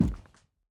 stepping sounds
Plastic_002.wav